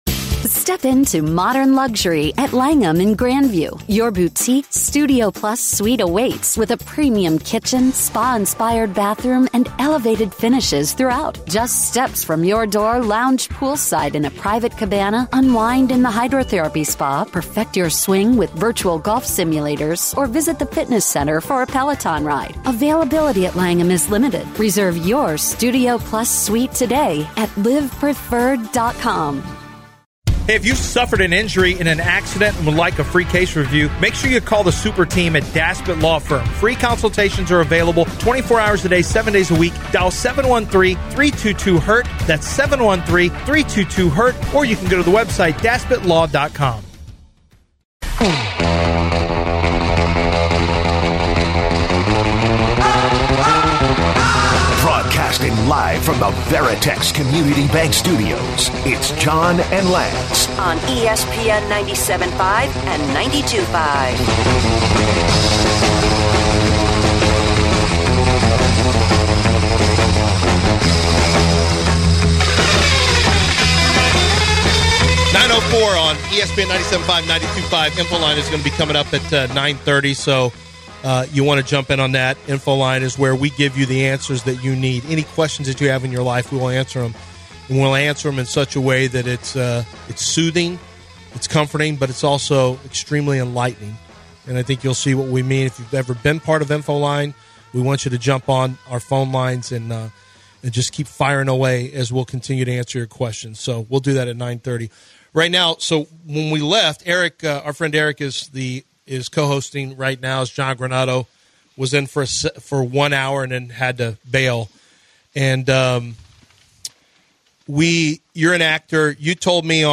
6-21 Hour 3: LeBron gets his guy... again + Info Line segment with listeners